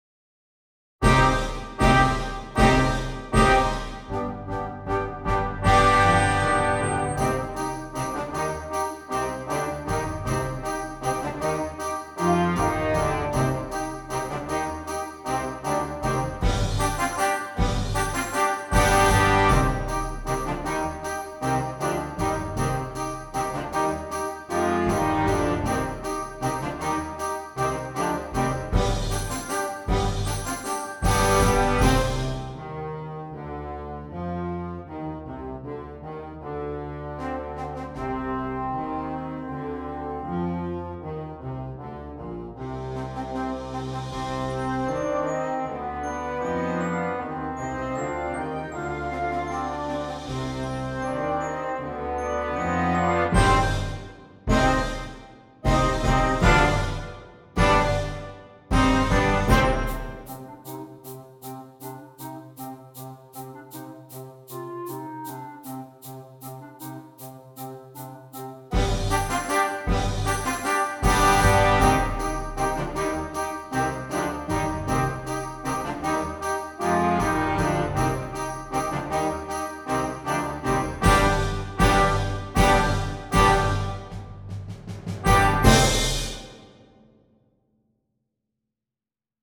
Concert Band
features the low brass and wind instruments